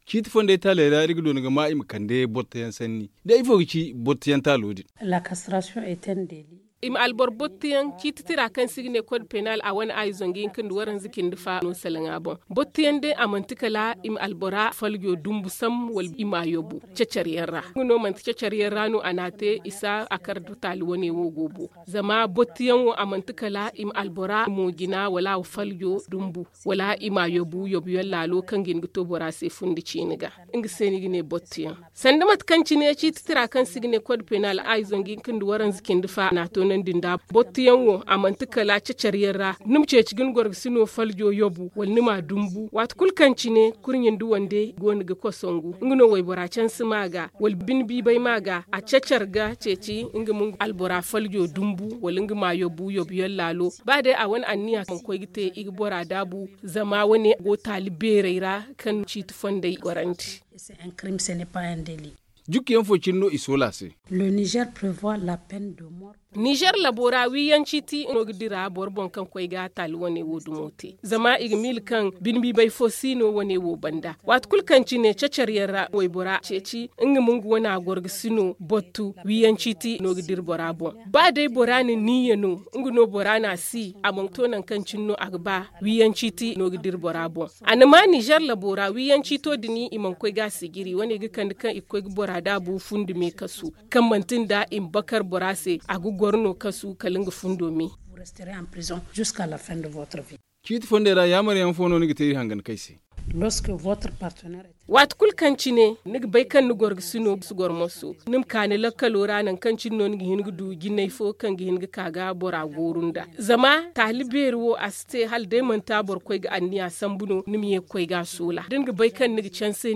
Le magazine en zarma